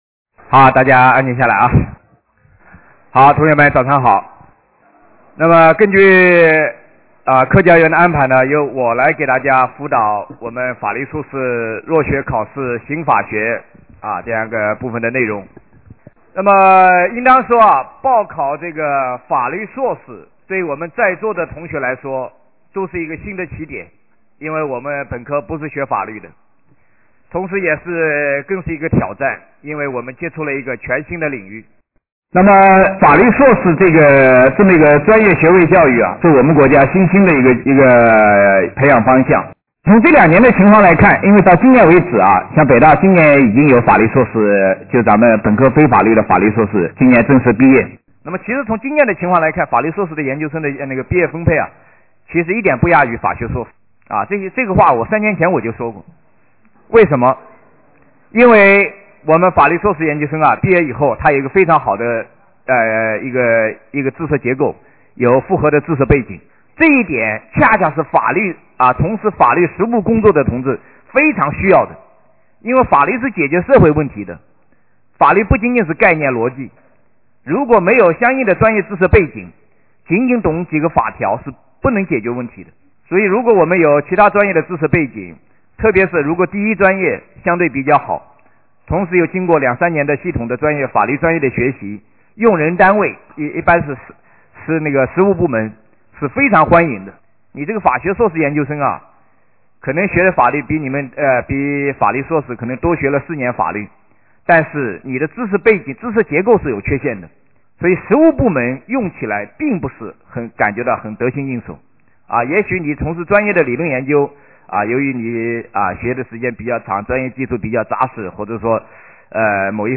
标题：北京大学法学院教授谈法律硕士前景
摘自：2004年科教园法律硕士基础班音频  下载